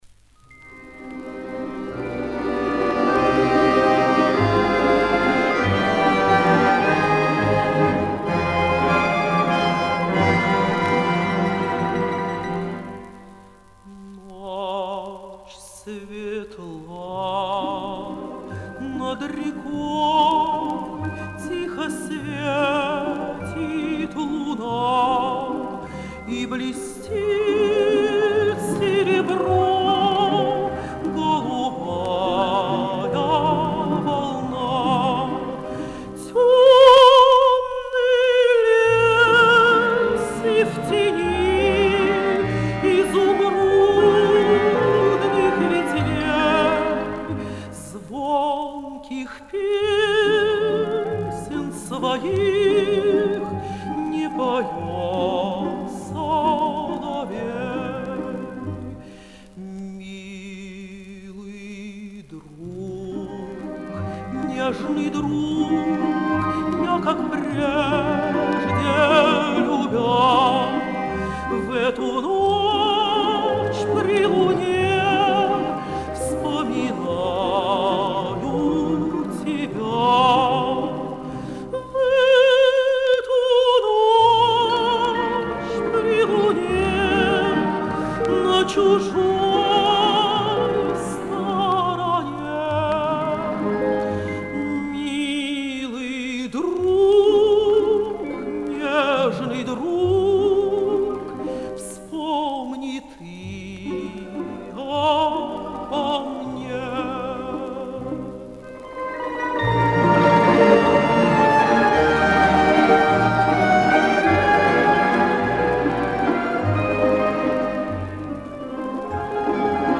13 августа. День рождения выдающейся русской певицы, Народной артистки РСФСР Валентины Левко